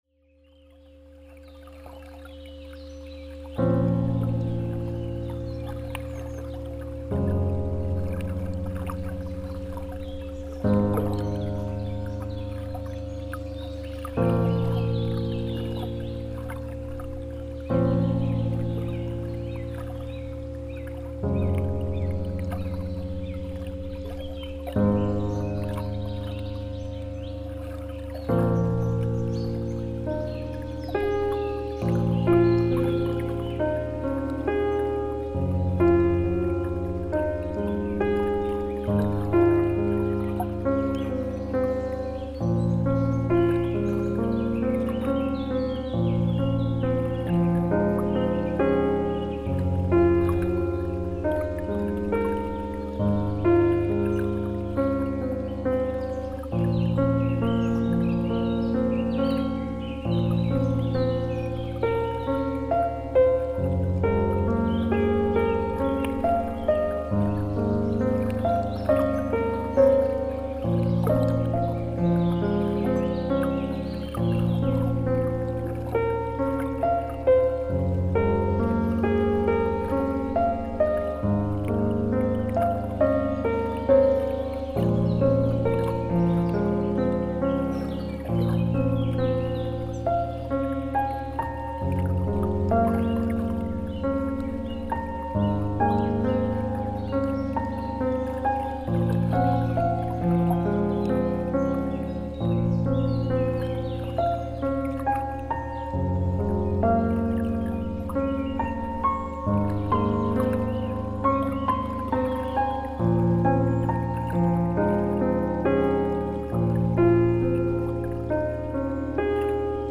• -1) Quatre fréquences spécifiques, sélectionnées en résonance avec les organes ciblés, pour stimuler leurs fonctions énergétiques et vibratoires.
• -2) Un accompagnement au piano accordé en 432 Hz (titre : Yūgen), fréquence naturelle réputée pour favoriser l’harmonie, la détente et l’alignement intérieur.
• -3) Une ambiance sonore inspirée de la nature (eau, vent, oiseaux…), qui enveloppe l’écoute dans une atmosphère apaisante et immersive.